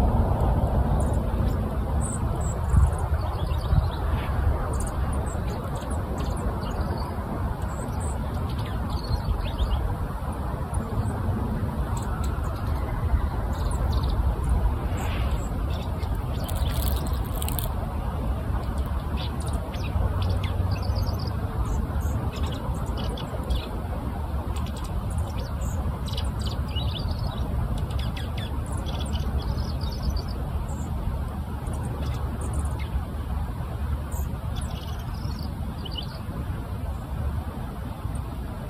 This song was heard in a nearby yard here at Commonland, near the
woods of the Six-Mile Creek natural area.